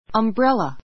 ʌmbrélə